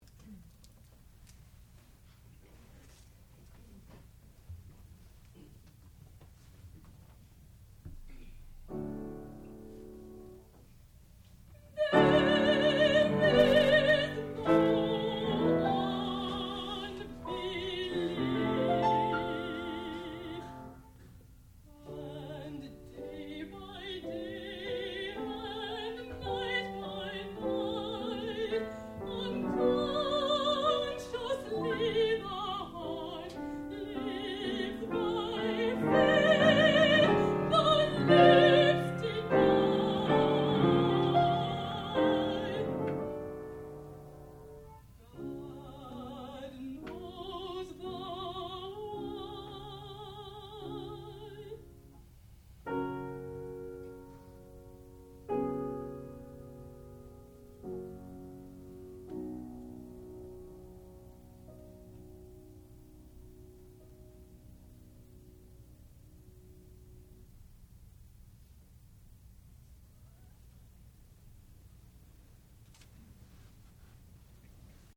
sound recording-musical
classical music
Master's Recital
mezzo-soprano